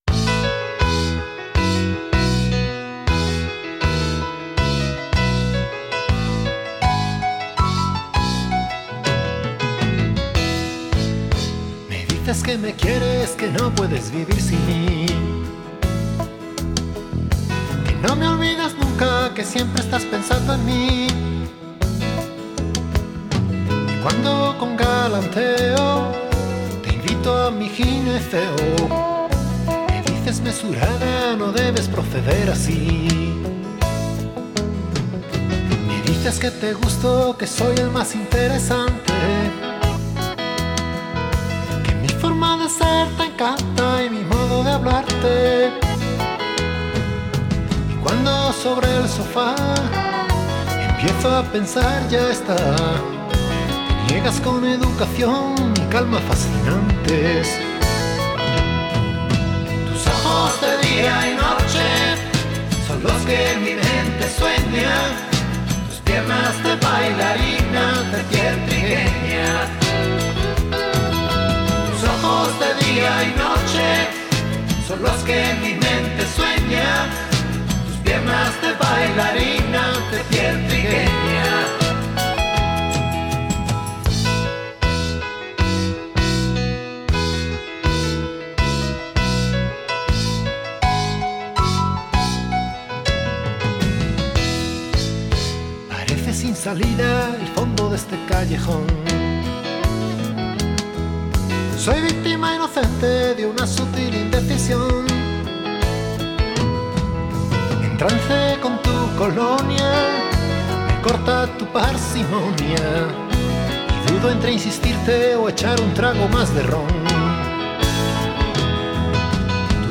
pop español